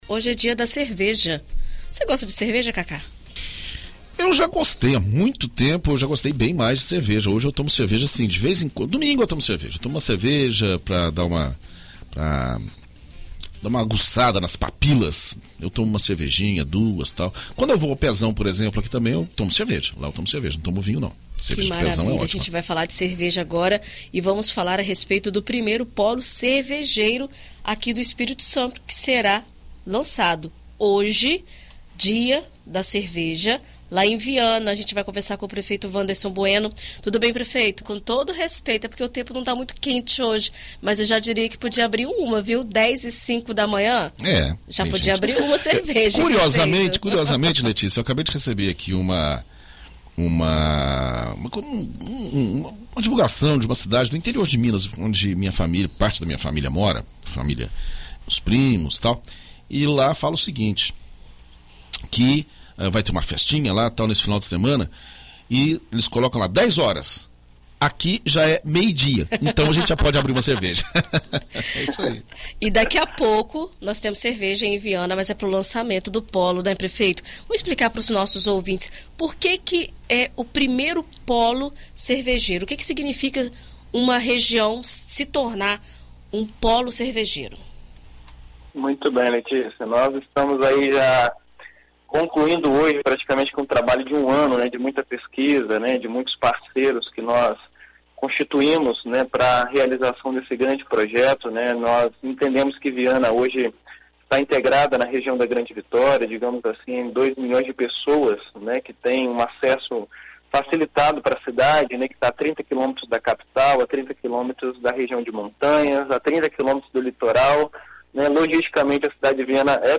Em entrevista à BandNews FM Espírito Santo nesta sexta-feira (05), o prefeito Wanderson Bueno fala sobre a implantação do espaço na cidade e como irá funcionar a instalação das cervejarias, que irão permitir a degustação da bebida.